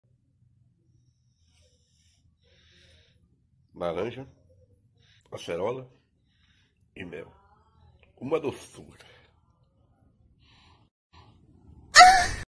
Man Drinking Orange Juice Meme